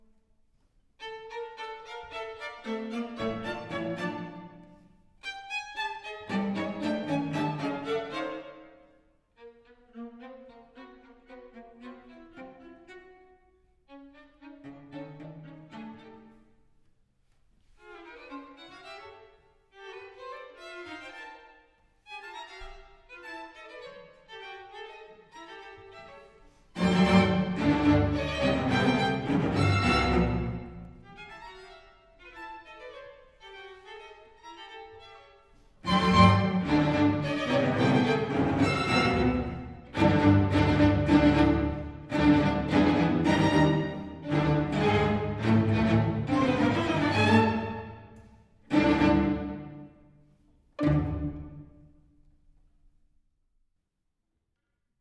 Romantische Szenen für Streichorchester